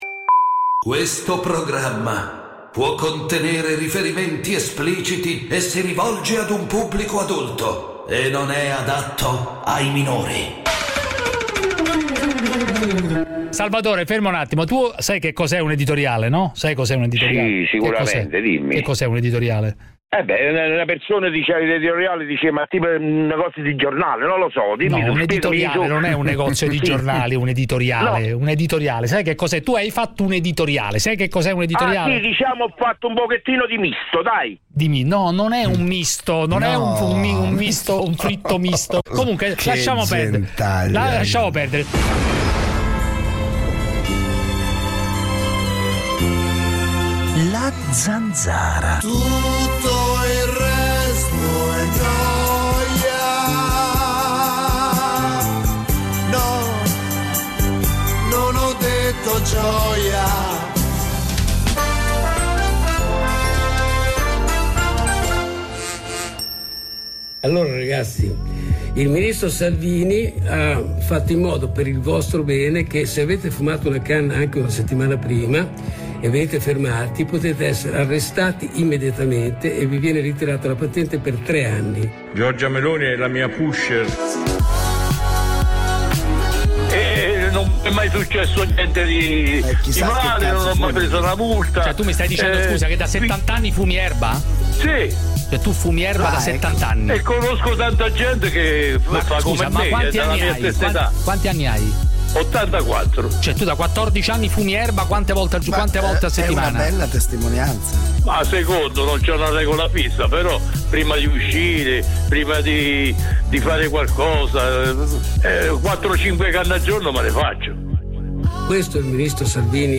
Giuseppe Cruciani con David Parenzo conduce "La Zanzara", l'attualità senza tabù, senza censure, senza tagli alle vostre opinioni. Una zona franca per gli ascoltatori, uno spazio nemico della banalità e del politicamente corretto, l'arena dove il primo comandamento è parlare chiaro.